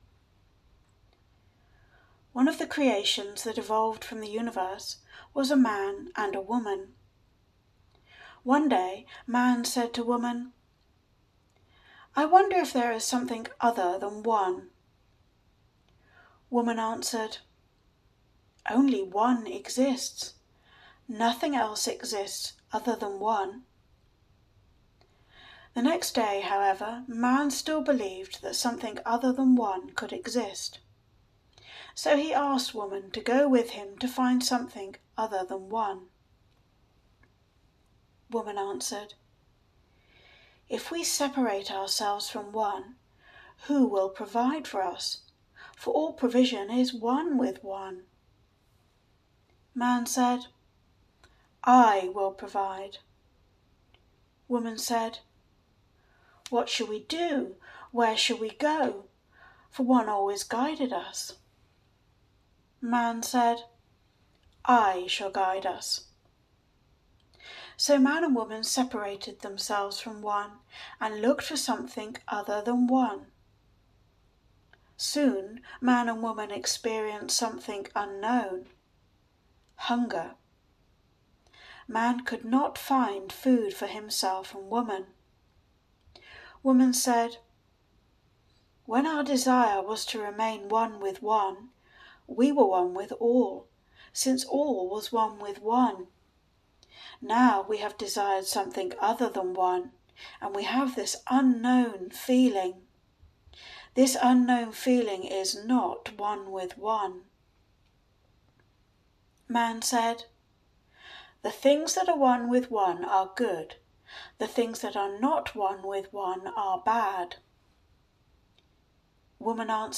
The Young Man and the Old Man - Audiobook